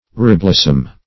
Reblossom \Re*blos"som\ (r[=e]*bl[o^]s"s[u^]m), v. i. To blossom again.